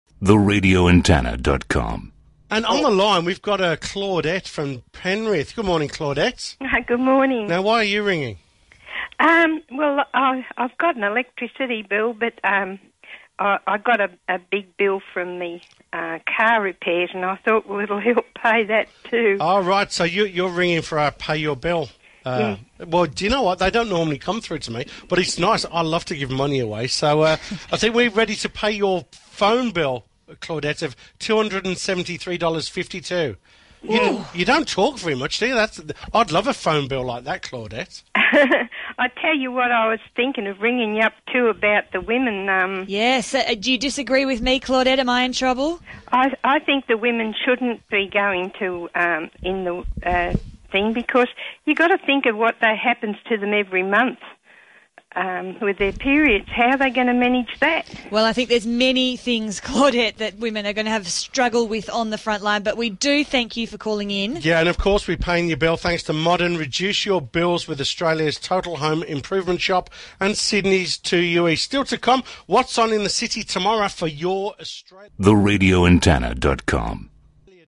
And the host’s opening words “what are you calling about?” Where’s the producer? Where’s the phone person?
And a caller so unimpressed by the prize that she’d rather talk about a previous topic.